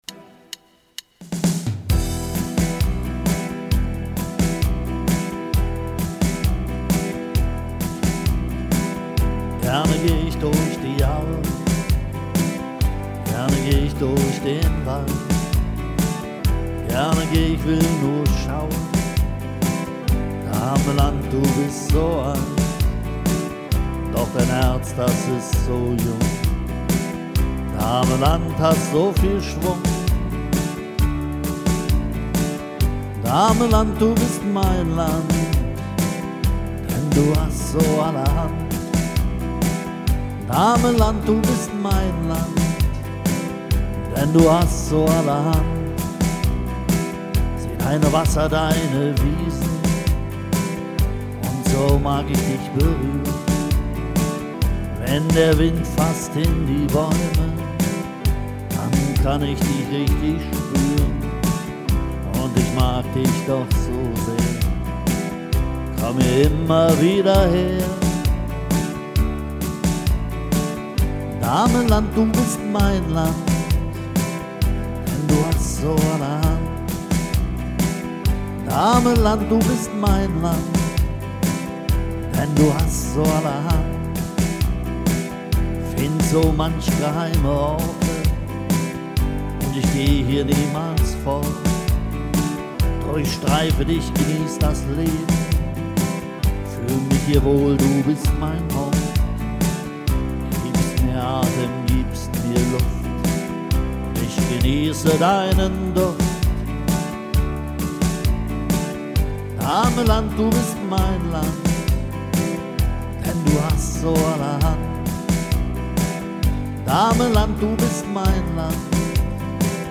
01__Dahmeland_du_bist_mein_Land___mit_Gesang.mp3